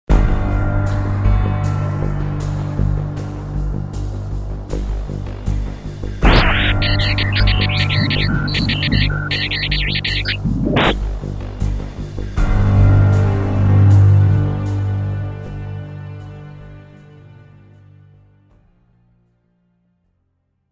Nos hemos colado en el Discord del contrincante y hemos grabado sus planes, aunque con algunas interferencias de extraña procedencia que no logramos descifrar.
victory.mp3: Audio file with ID3 version 2.3.0, contains:MPEG ADTS, layer III, v2,  64 kbps, 22.05 kHz, JntStereo
Escuchamos una musica, pero de un instante a otro hay una presunta interferencia.